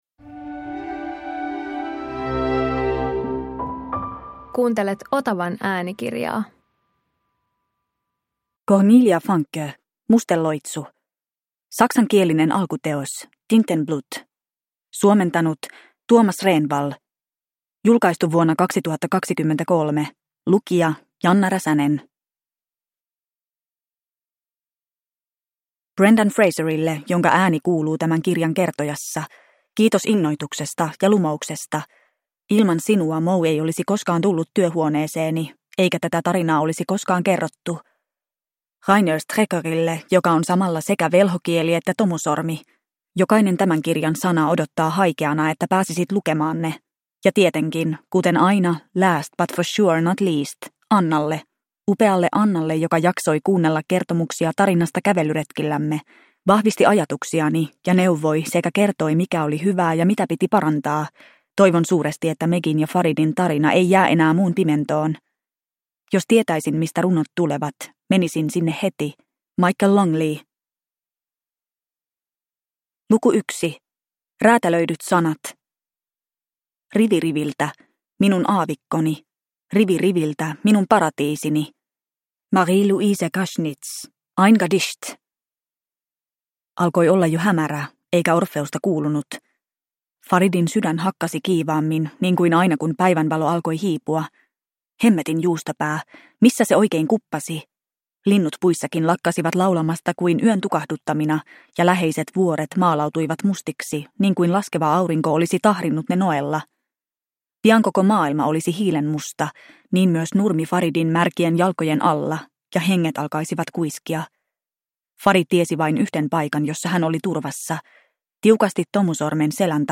Musteloitsu – Ljudbok – Laddas ner